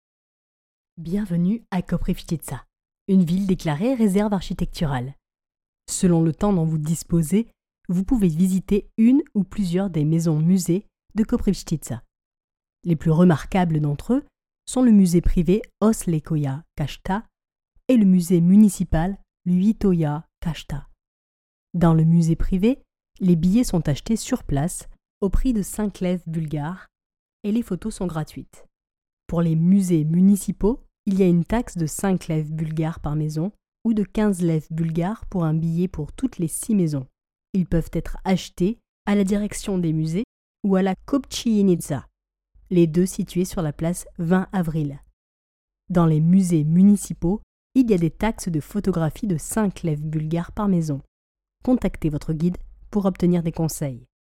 Audioguide Voix Off